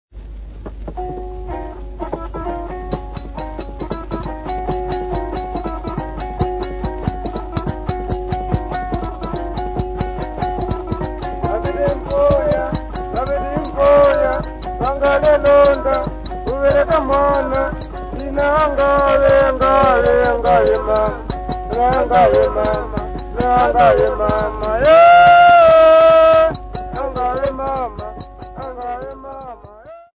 Yao men
Popular music--Africa
Field recordings
Africa Malawi city not specified f-mw
sound recording-musical
Indigenous music